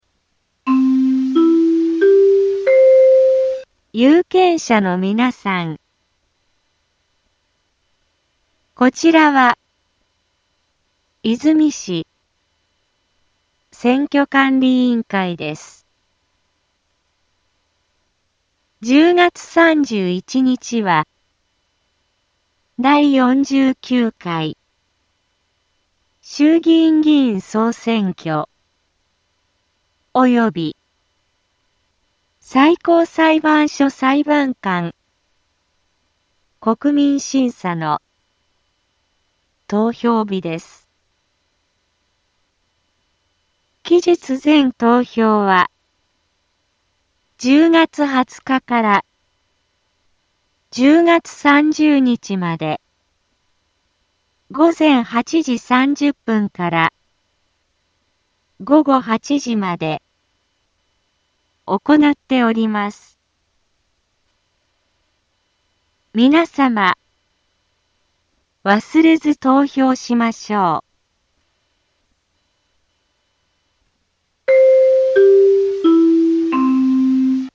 BO-SAI navi Back Home 災害情報 音声放送 再生 災害情報 カテゴリ：通常放送 住所：大阪府和泉市府中町２丁目７−５ インフォメーション：有権者のみなさん こちらは和泉市選挙管理委員会です １０月３１日は、第４９回衆議院議員総選挙及び最高裁判所裁判官国民審査の投票日です 期日前投票は、１０月２０日から１０月３０日まで午前８時３０分から午後８時まで行なっております 皆様、忘れず投票しましょう